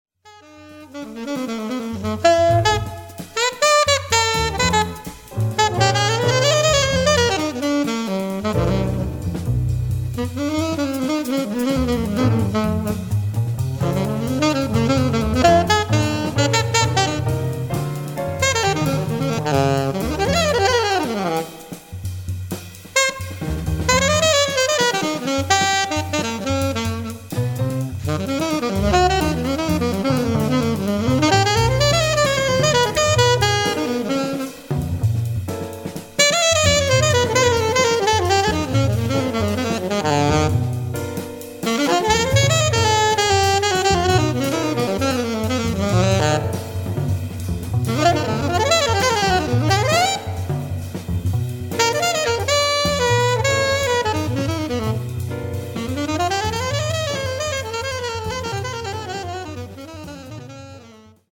Le modèle Firenze a été conçu pour les saxophonistes qui recherchent un son projectif pas excessivement clair.
Le premier modèle se distingue pour sa simplicité d’émission (parois de la fenêtre plates perpendiculaires au plafond) et un son centré ; le second modèle est caractérisé par un corps plus grand, une profondeur du son qui est plus complexe (grande chambre, parois de la fenêtre concaves).